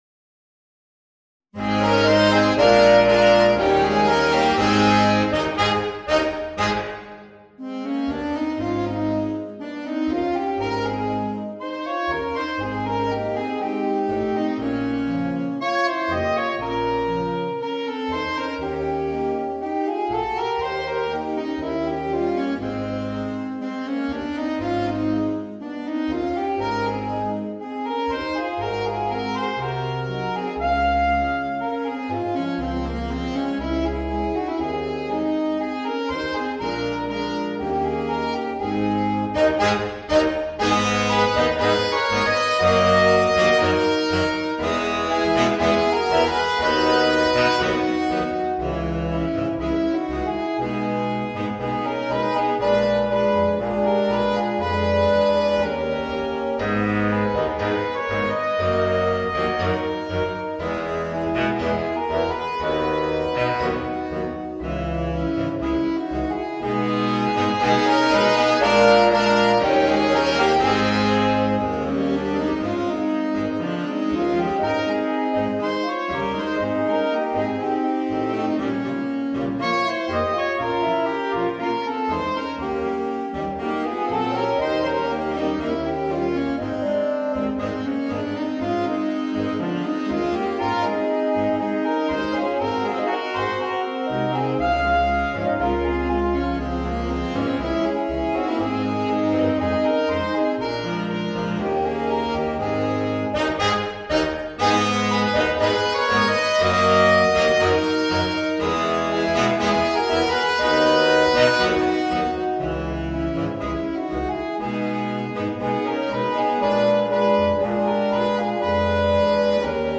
Voicing: 5 Saxophones